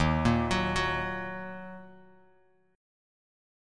Ebm Chord
Listen to Ebm strummed